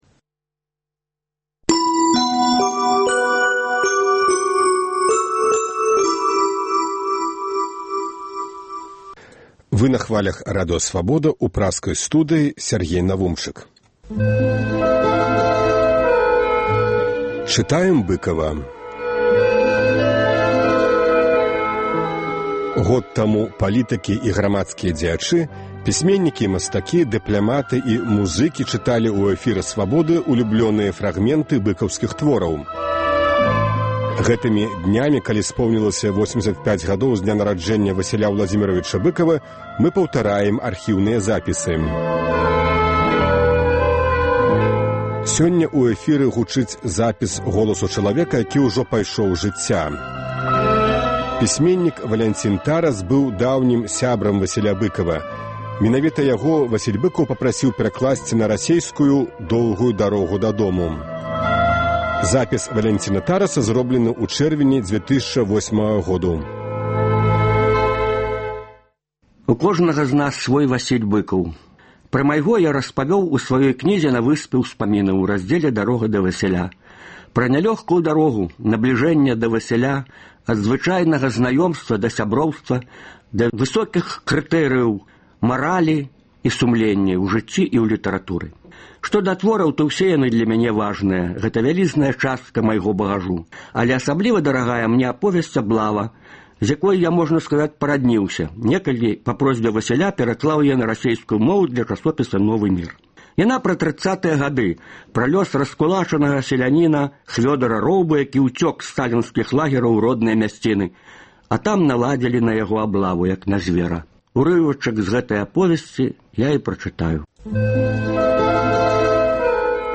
Вядомыя людзі Беларусі чытаюць свае ўлюбёныя творы Васіля Быкава. Сёньня гучыць запіс голасу чалавека, які ўжо пайшоў з жыцьця. Пісьменьнік Валянцін Тарас быў даўнім сябрам Васіля Быкава.